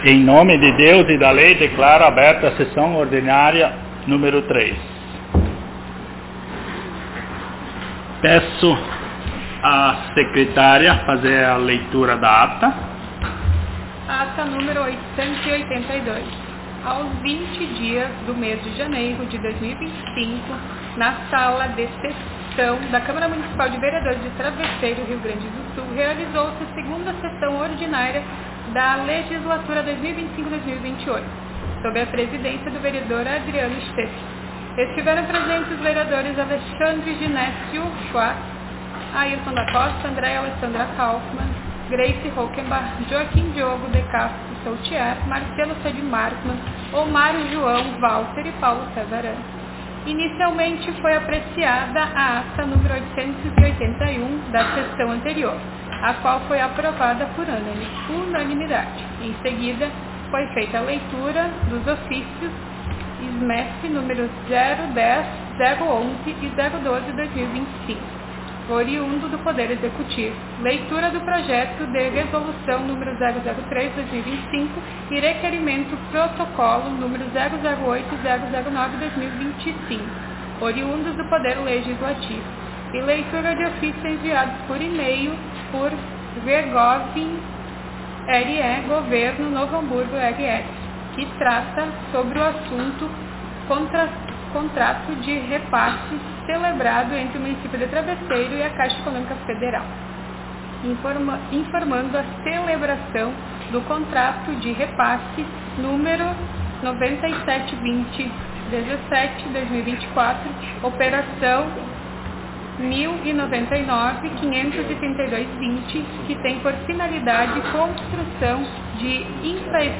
Aos 03 (três) dias do mês de fevereiro do ano de 2025 (dois mil e vinte e cinco), na Sala de Sessões da Câmara Municipal de Vereadores de Travesseiro/RS, realizou-se a Terceira Sessão Ordinária da Legislatura 2025-2028.